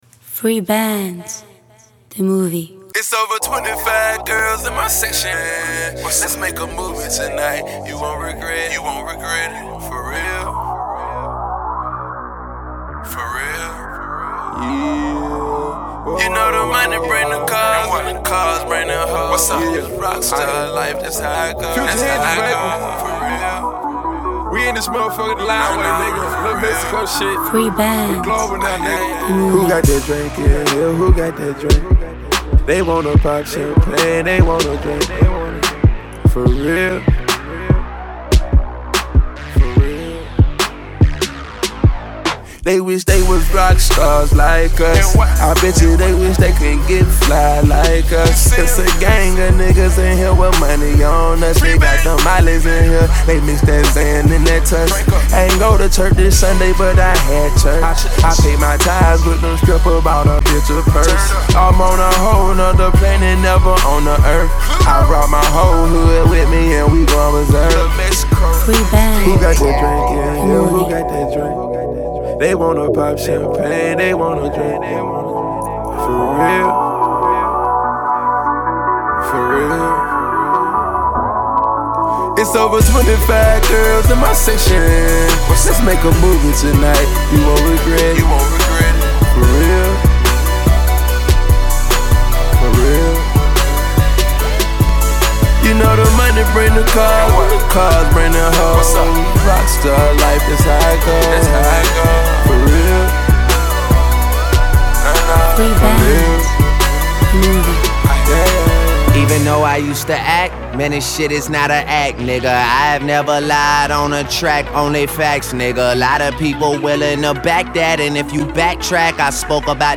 Autotune in 2013?
The beat change didn’t do this one any favors either.